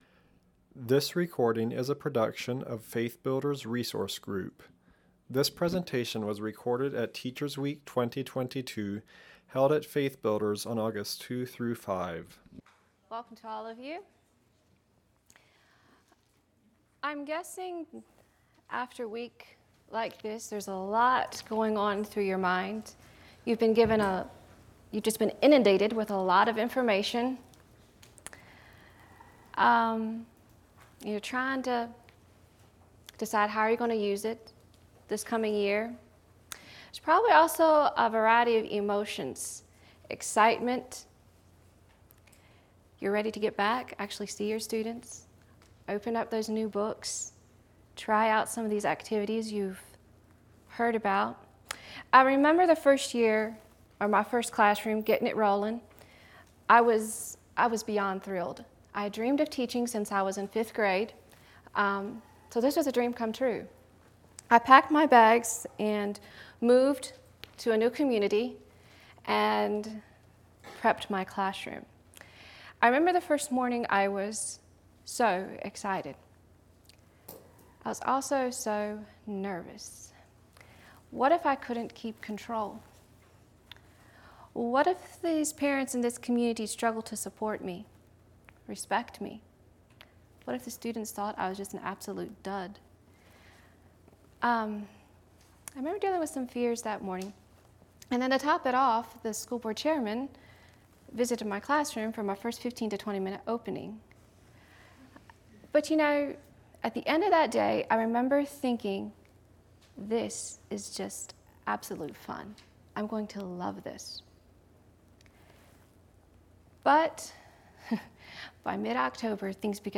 To fulfill your commitment to be the leader in your classroom, you need to prepare for the year, manage, and teach in a way that controls the leaning and calls them to follow, and then reflect and reshoot. This is a general elementary session focusing on first- and second-year teachers, taught from the perspective of an upper-elementary teacher.